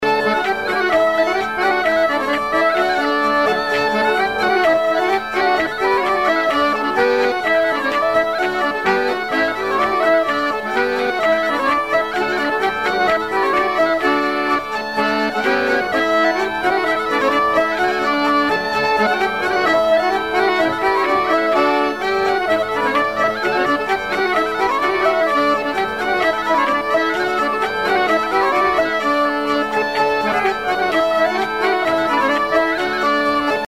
Couplets à danser
branle : courante, maraîchine
bal traditionnel à la Minoterie, à Luçon
Pièce musicale inédite